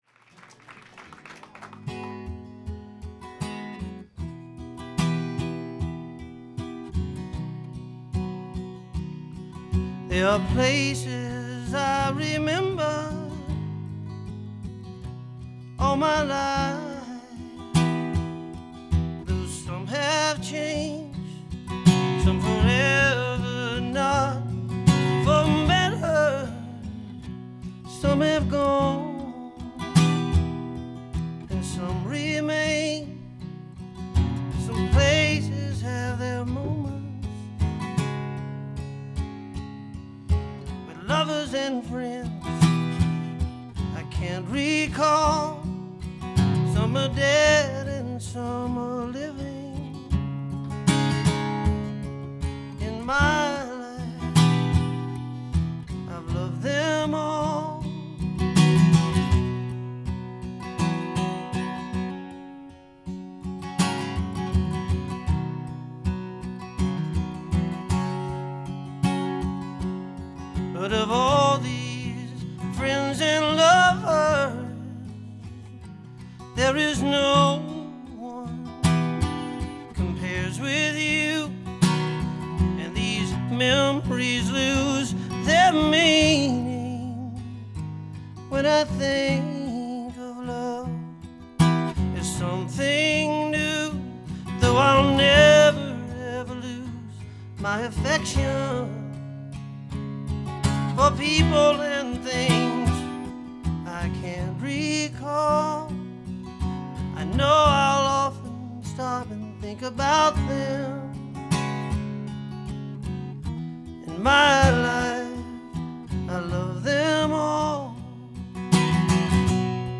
tribute concert
without rehearsal